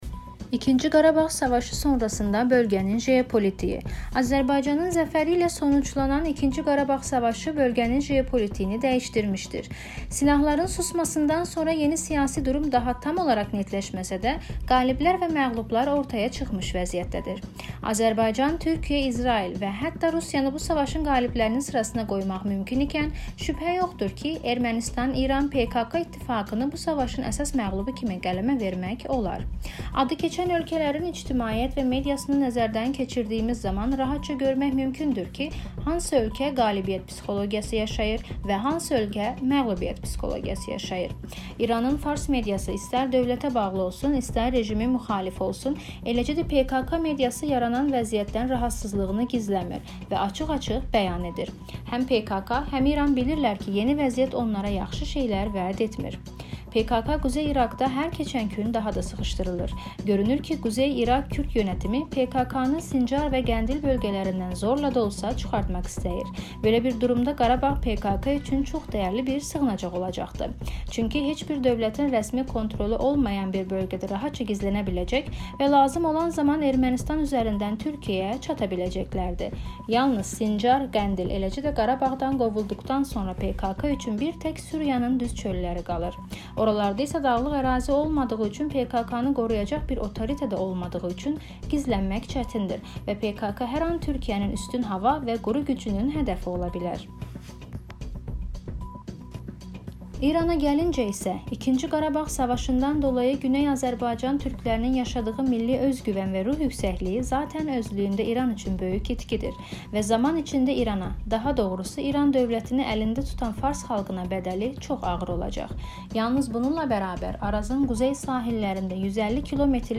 Nostalgic azerbaycani jazz